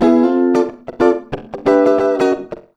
92FUNKY  9.wav